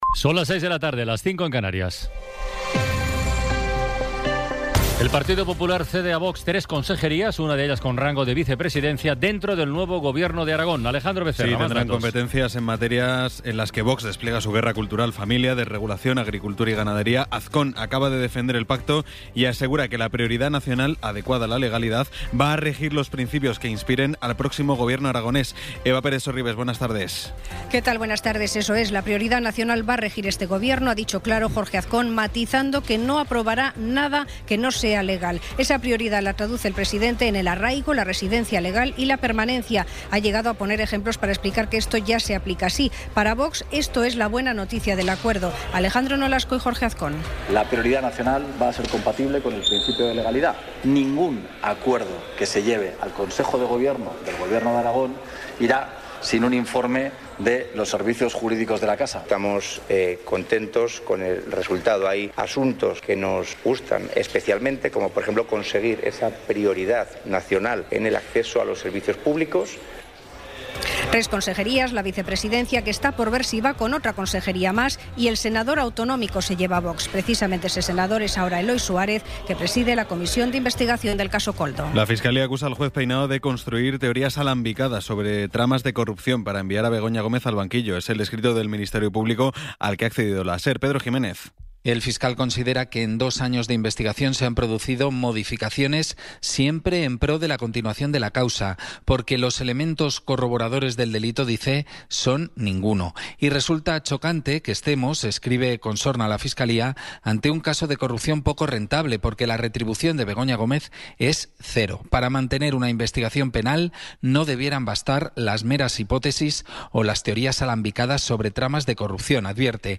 Resumen informativo con las noticias más destacadas del 22 de abril de 2026 a las seis de la tarde.